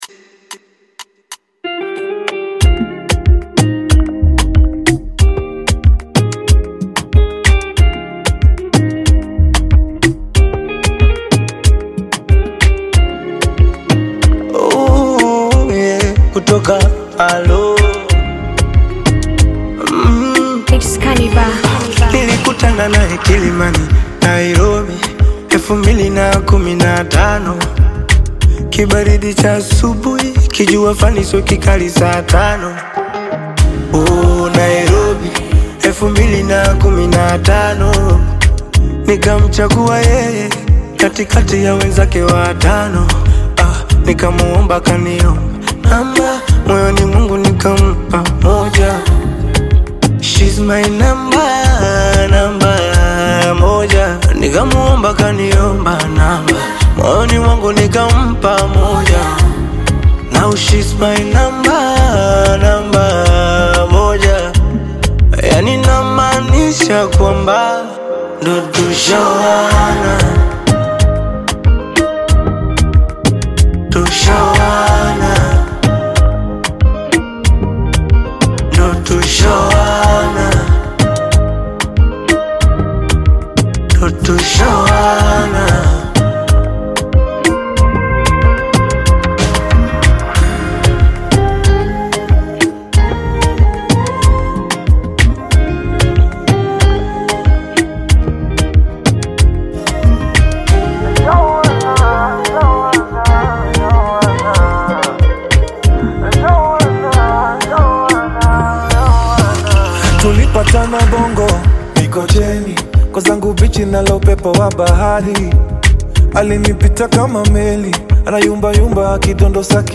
Afro-Pop/Bongo-Flava collaboration
Genre: Bongo Flava